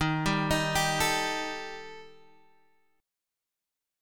EbMb5 chord